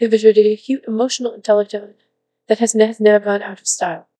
coqui-tts - a deep learning toolkit for Text-to-Speech, battle-tested in research and production